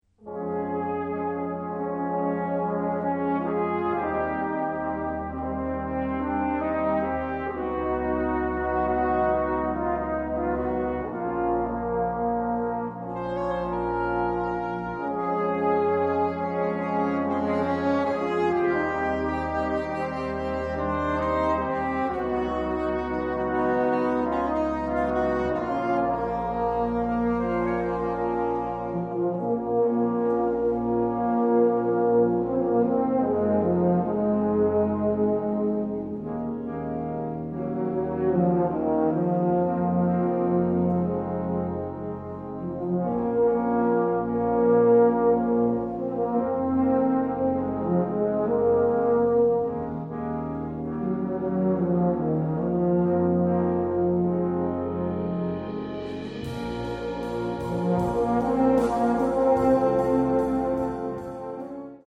A brilliant pop song